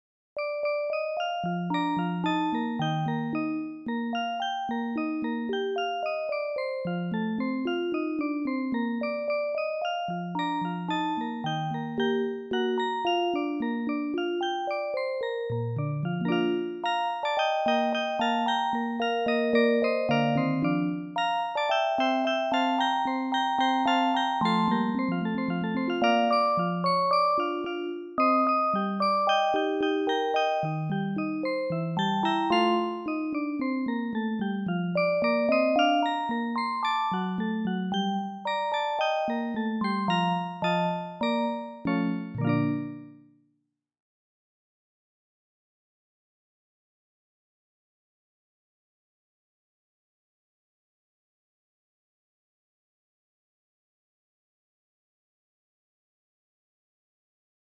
オルゴール通常バージョン